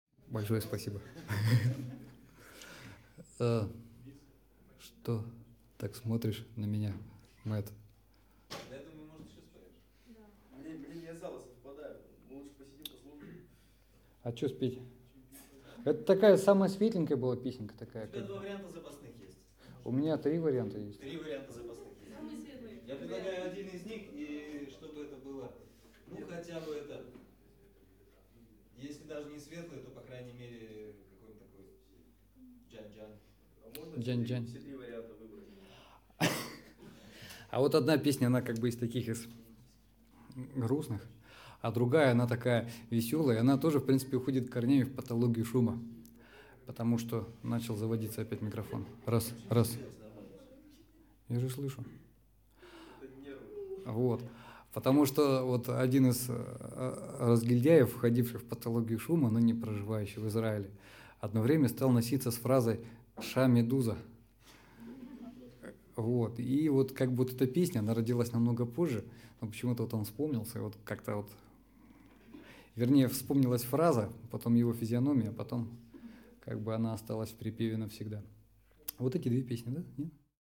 12,5 :) - фрагмент разговора с залом
• Исполняет: Проект Ночная Атака